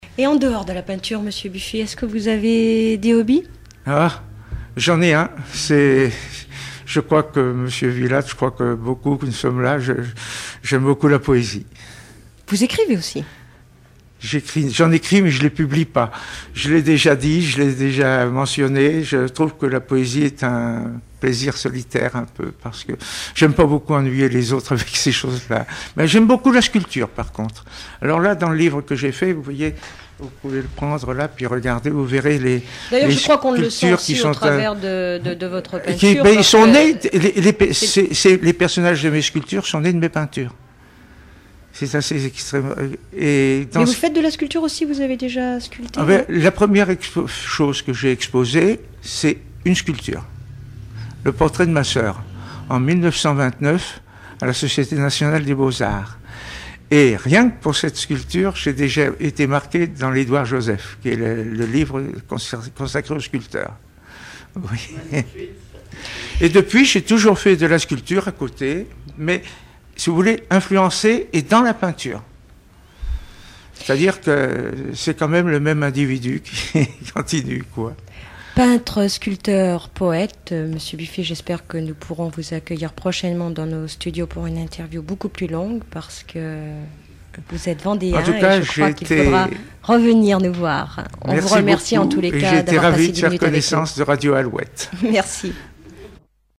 Enquête Alouette FM numérisation d'émissions par EthnoDoc
Catégorie Témoignage